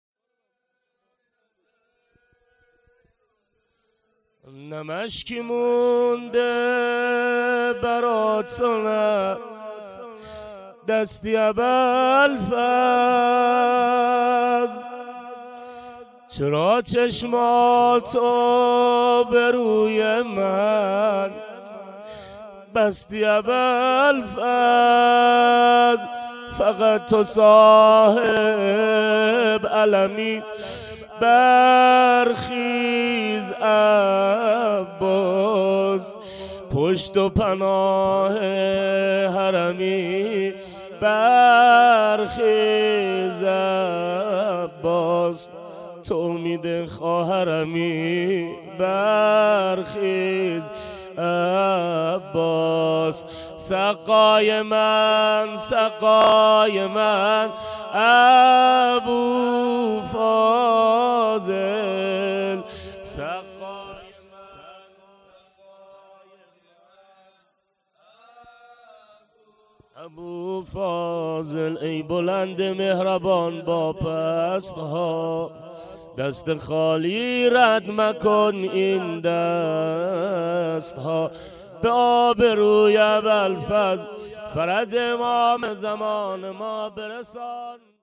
شب شهادت امام سجاد 98